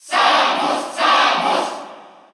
Category: Crowd cheers (SSBU) You cannot overwrite this file.
Samus_Cheer_Russian_SSBU.ogg.mp3